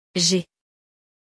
• Prononciation : [ʒe]